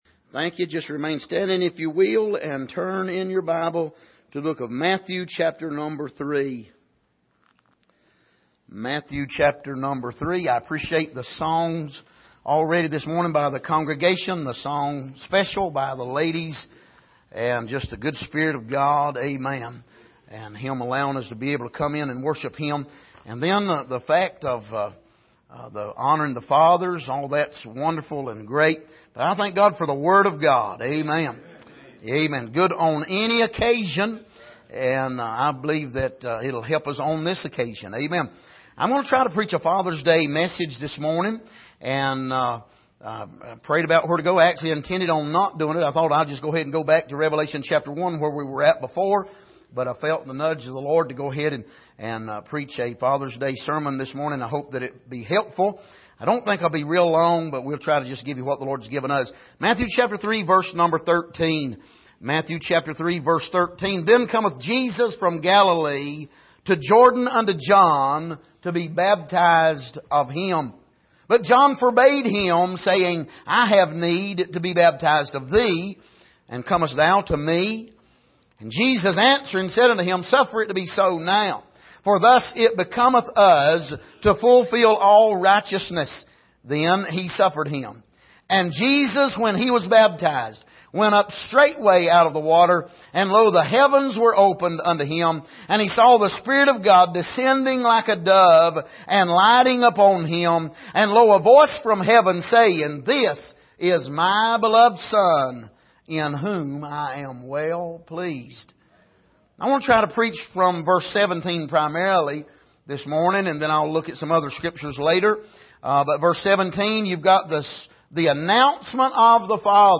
Passage: Matthew 3:17 Service: Sunday Morning